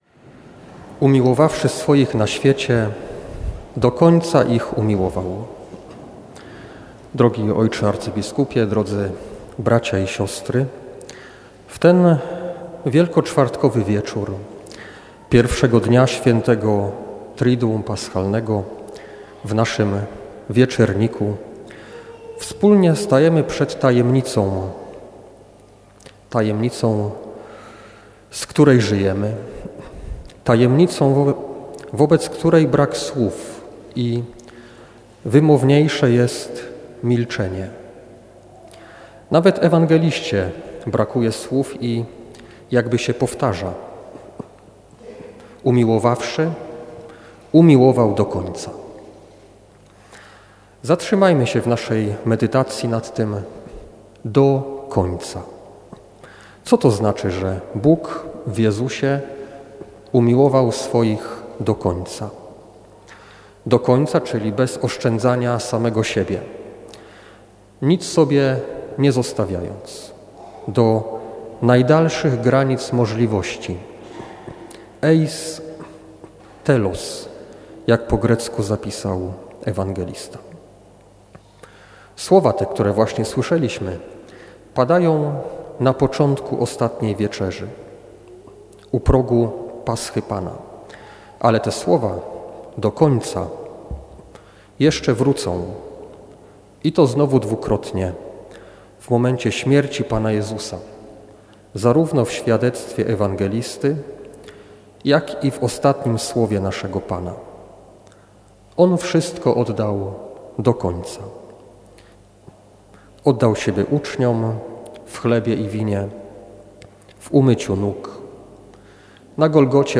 W wielkoczwartkowy wieczór w Katedrze Wrocławskiej sprawowana była Liturgia Wieczerzy Pańskiej.